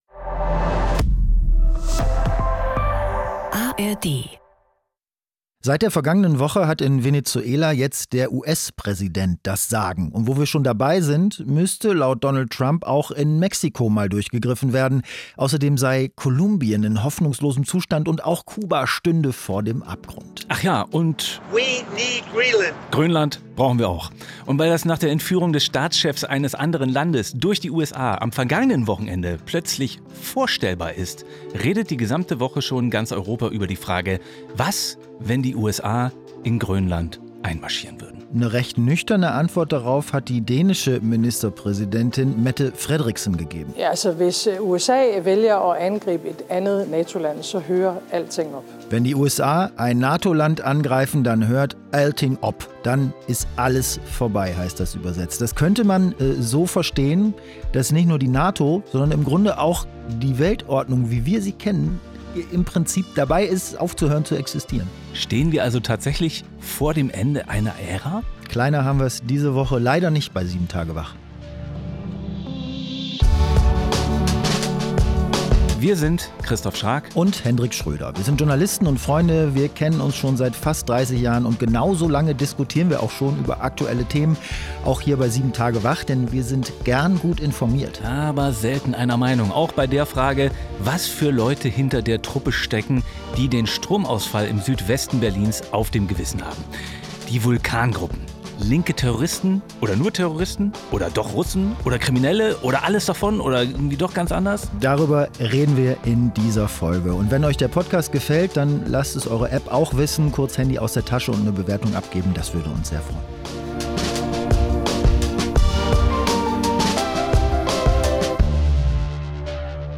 Zwei Freunde, zwei Meinungen, ein News-Podcast